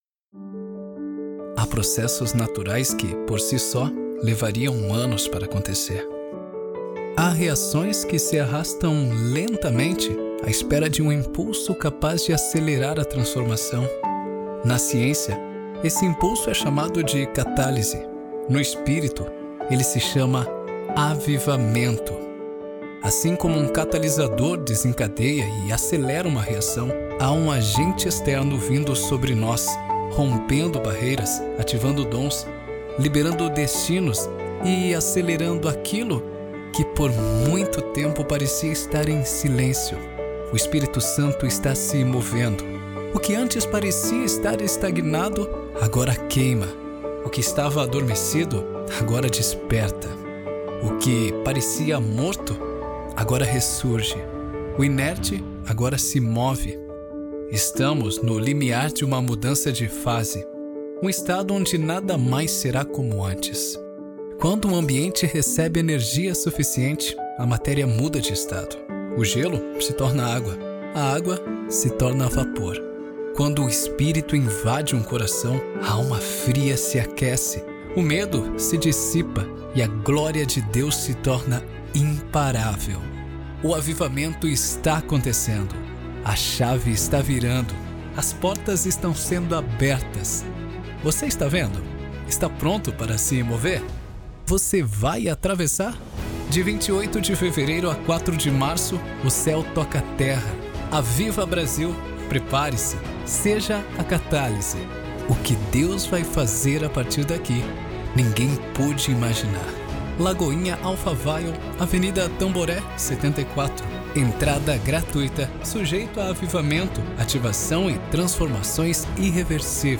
AVIVA BRASIL, VOZ PARA EVENTOS GOSPEL: